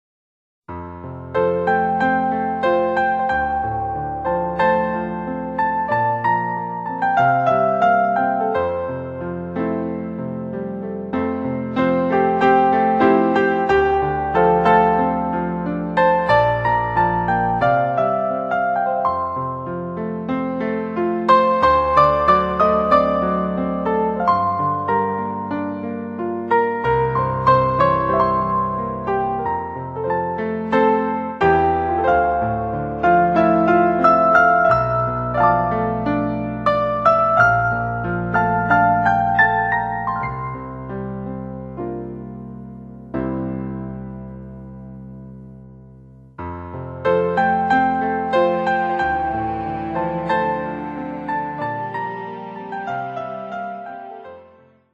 〜 ポップスアルバム。
オリジナルの音源で録音し、マスタリングを施しました。